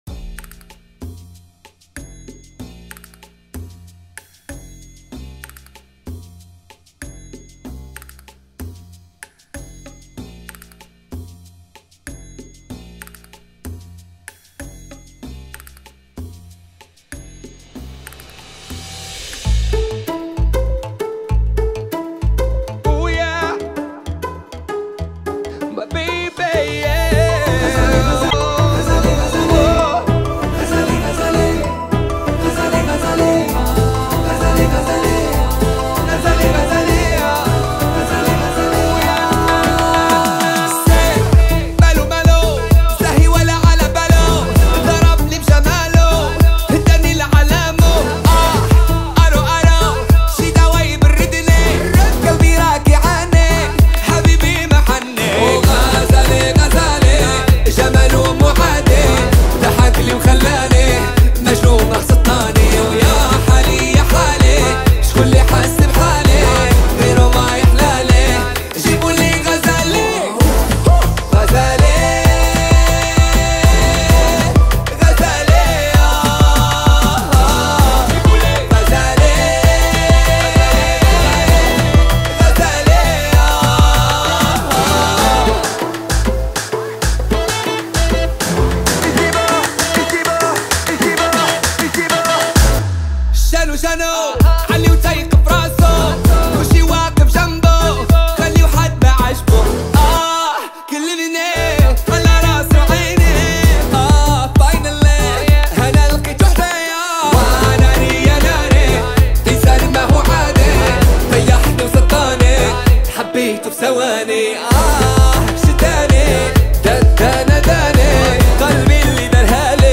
دانلود آهنگ شاد عربی